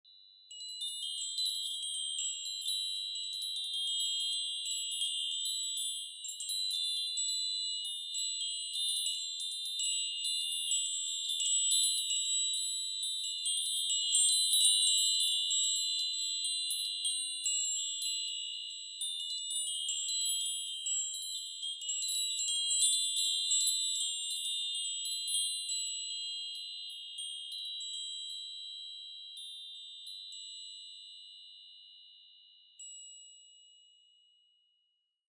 Meinl Sonic Energy Mini Tree of Life Chakra Chime 12"/30 cm - Silver (TOLCC12MINI)